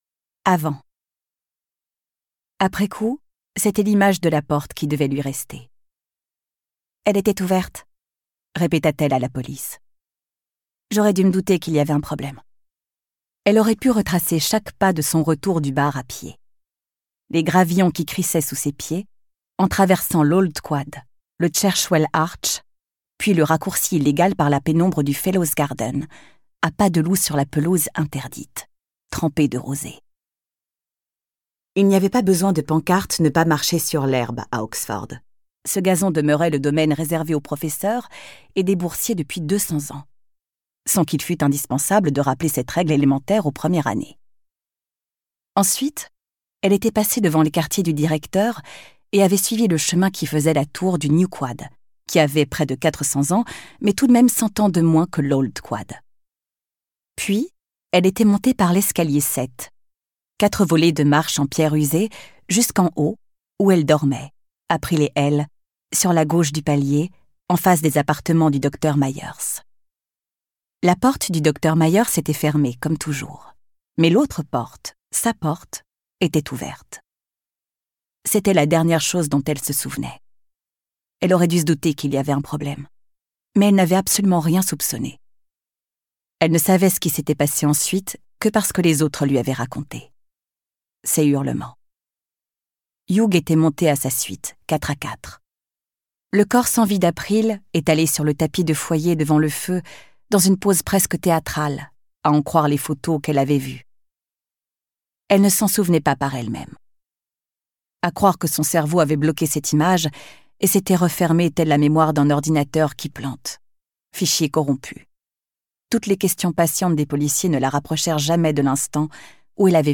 Un récit haletant porté par la lecture enveloppante et dynamique